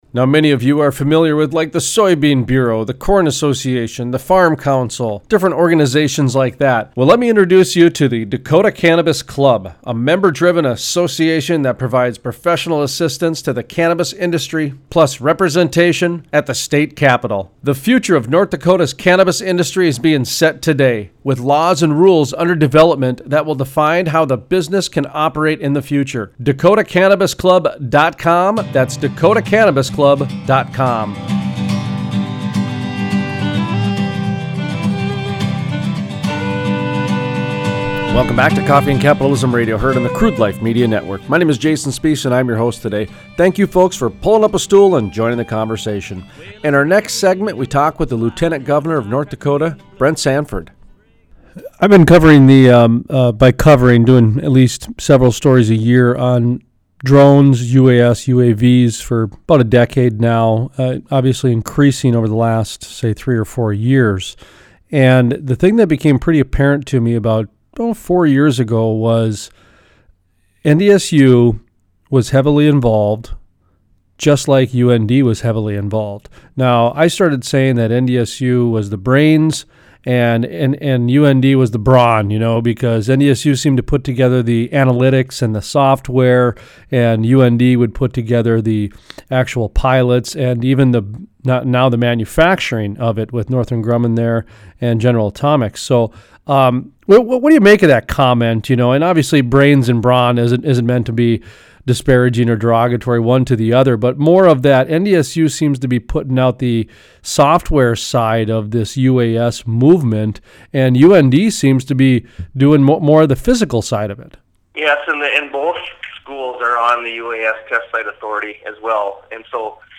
Interview: Brent Sanford, Lt. Gov North Dakota Sanford talks about the UAS industry in North Dakota and how the region is producing entrepreneurs and start ups and how the local universities are benefiting as well.